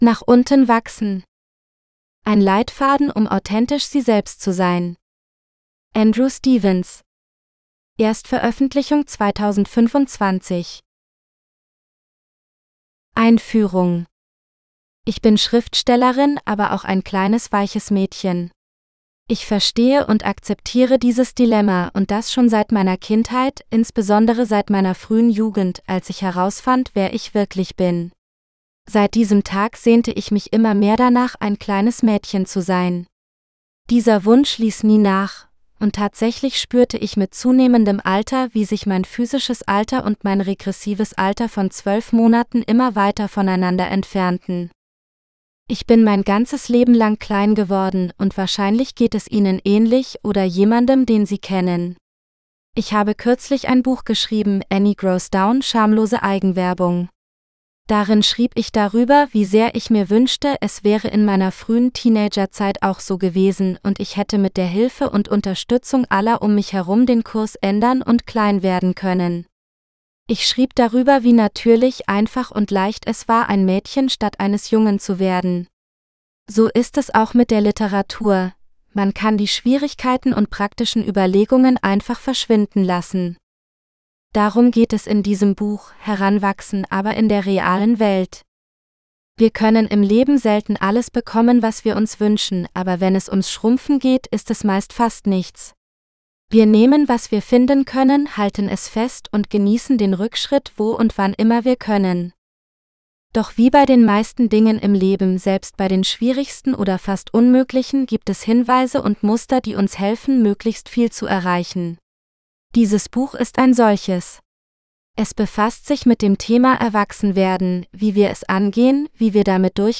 Growing Down GERMAN (AUDIOBOOK – female): $US5,75